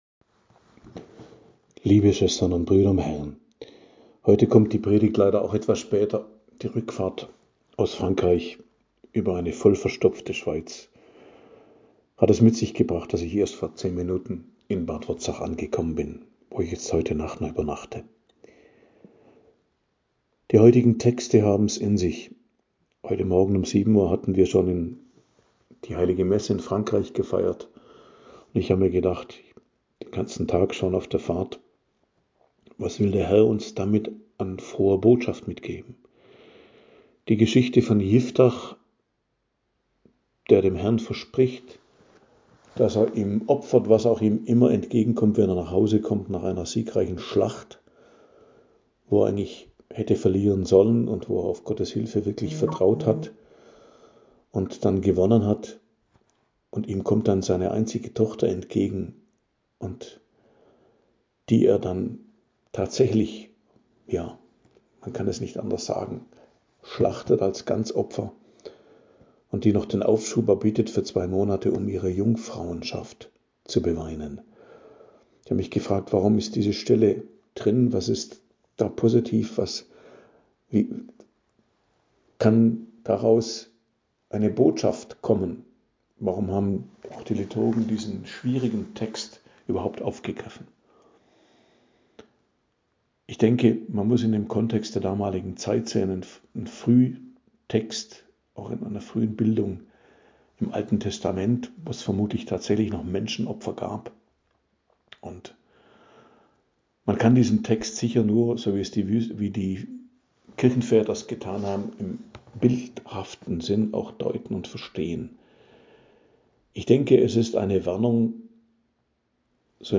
Predigt am Donnerstag der 20. Woche i.J., 21.08.2025